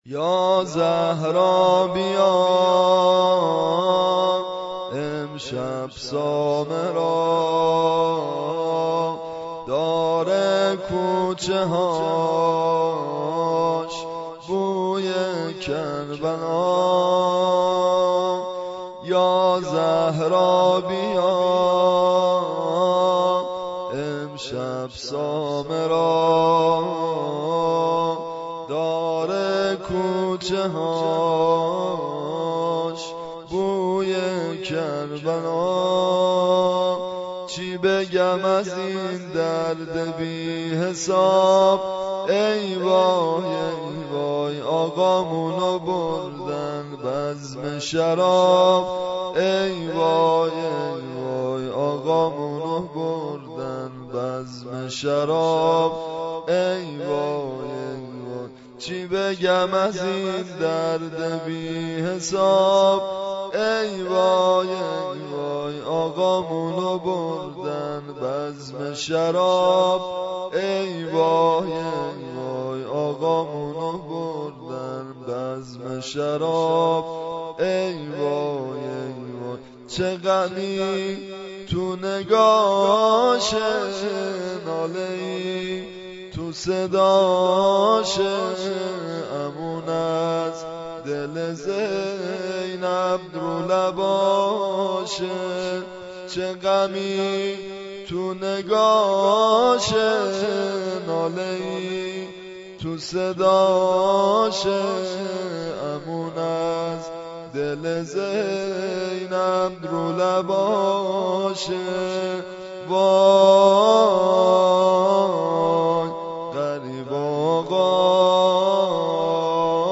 زمینه‌ي زیباي شهادت امام هادی(ع) به همراه سبک